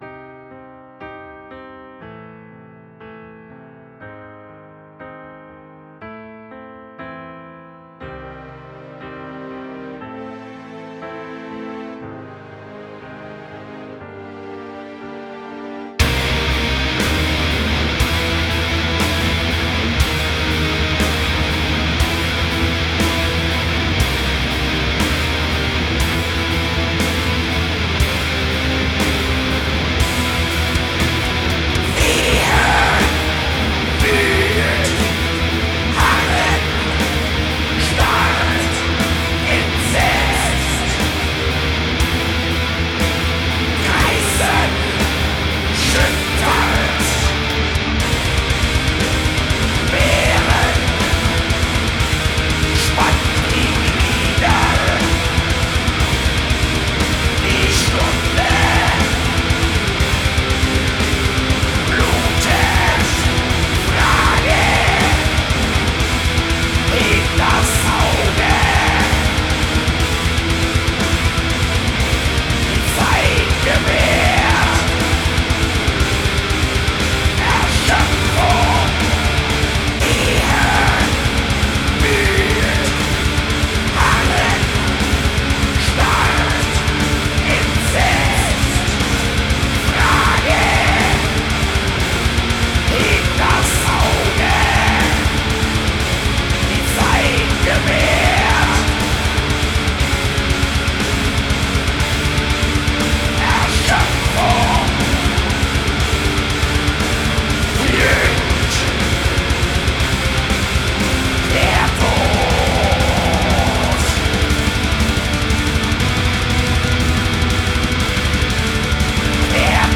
(soll mein erster versuch in richtung black metal sein...):
vocals über meinen laptop-speaker. :eek:
blackmetal2.mp3